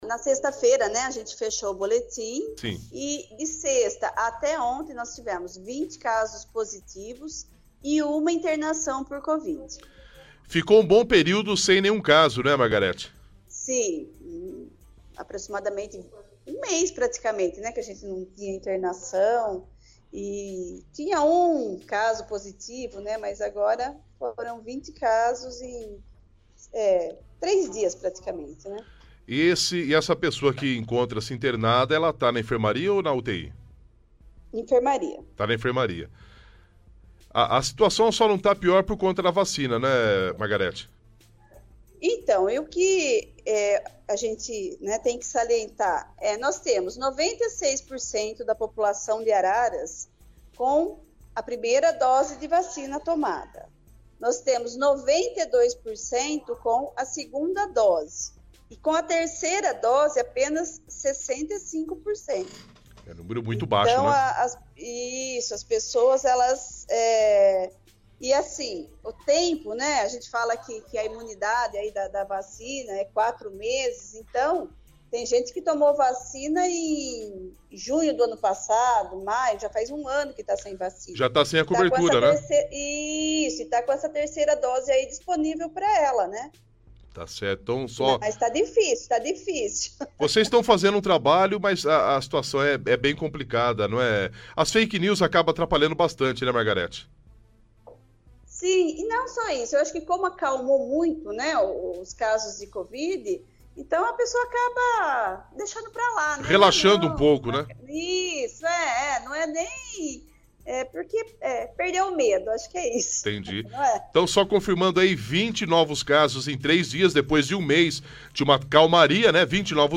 durante o programa Manhã Total, pela Rádio Vem Com A Gente